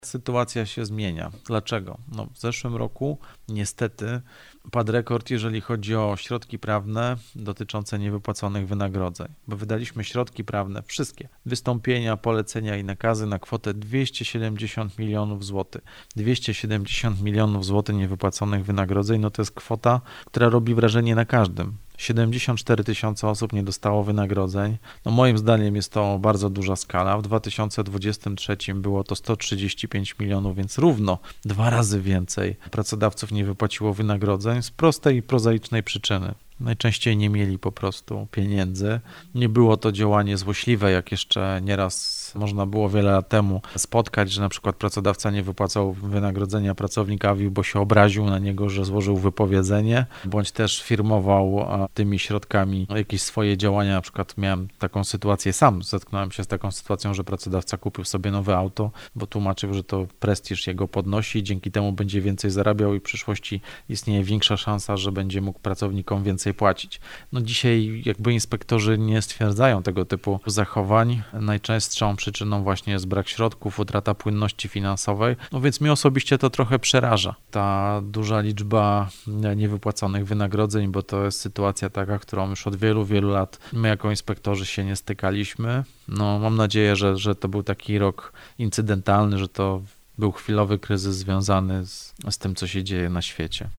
-W ubiegłym roku padł rekord, jeśli chodzi o niewypłacone środki wynagrodzeń, mówi minister Marcin Stanecki – Główny Inspektor Pracy.